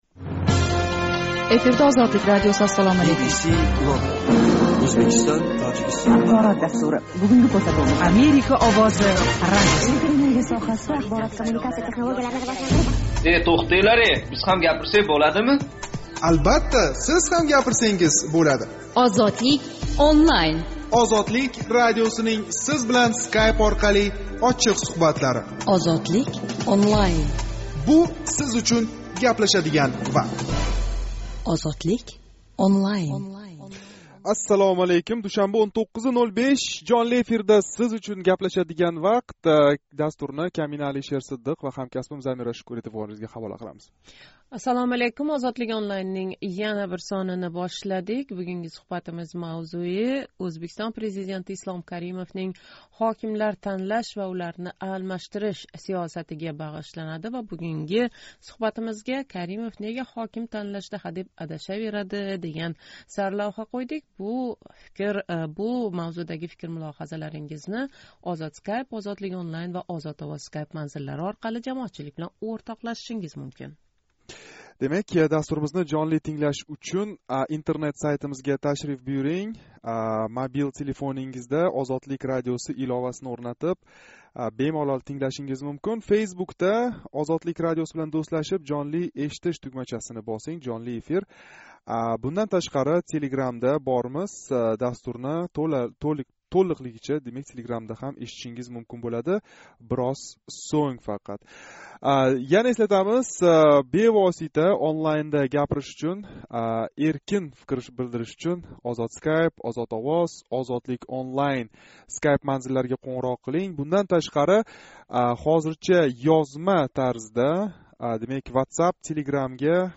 Каримов "ишонч билан" ҳокимликка қўядиган кадрлар¸ нега Каримов айтмоқчи¸ "ишончни оқламайди"лар? Душанба кунги жонли мулоқотда шу ҳақда гаплашамиз.